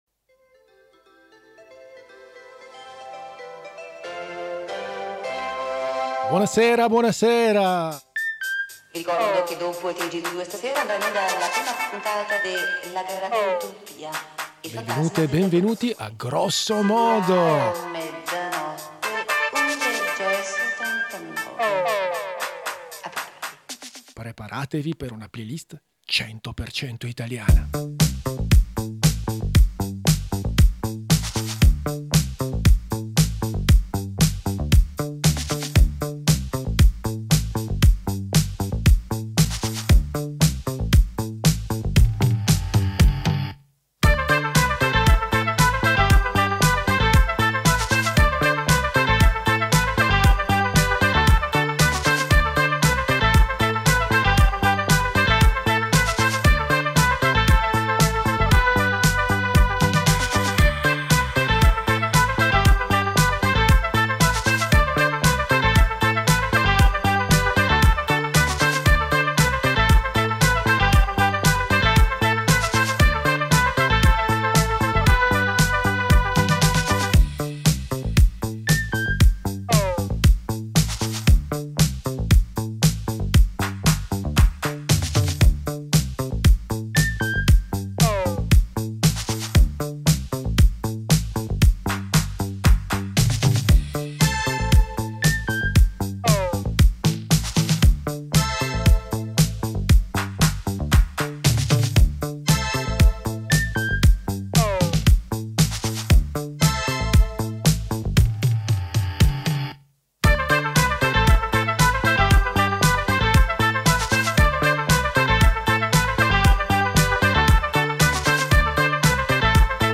Dans cet épisode, pas de cours d’italien, mais une playlist 100 % italienne pour danser pendant le réveillon. La musique idéale pour siroter votre prosecco et grignoter votre foie gras !